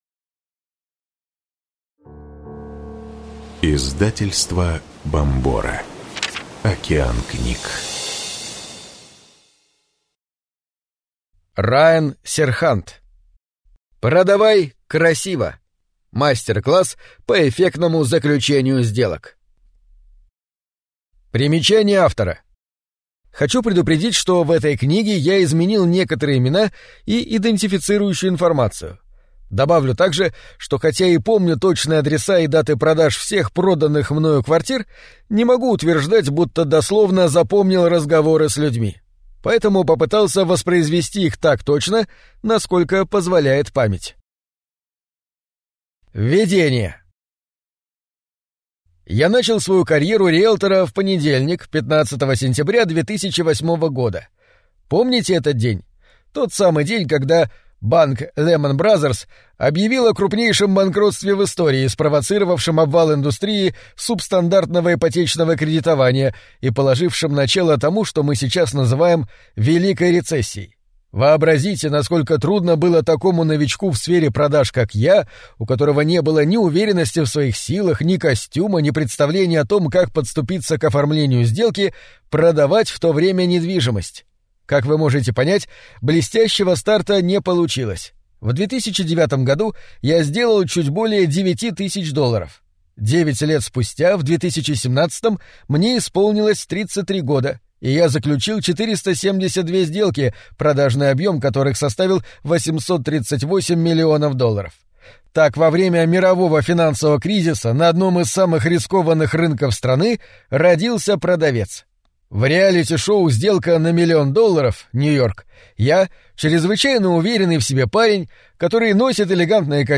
Аудиокнига Продавай красиво. Мастер-класс по эффектному заключению сделок | Библиотека аудиокниг